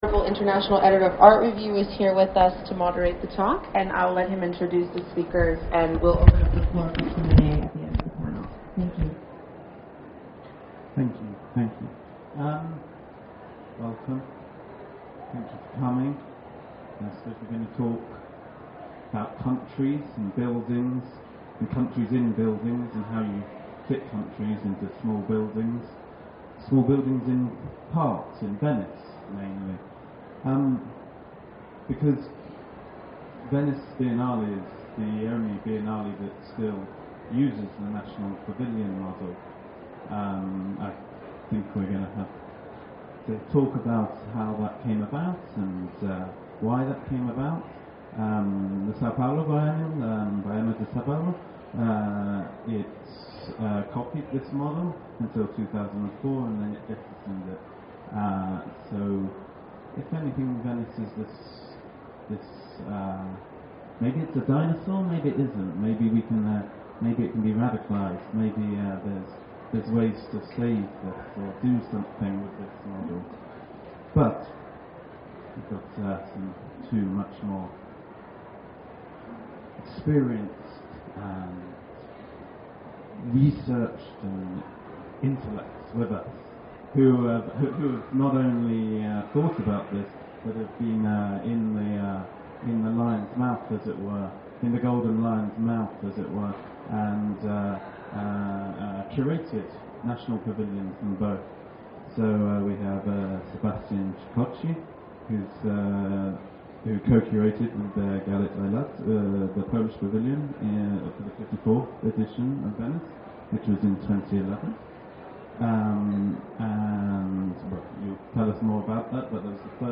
La redazione di KABUL magazine, in collaborazione con ATP DIARY, ha deciso di rendere disponibili le registrazioni audio di alcuni dei talk di Miart 2017.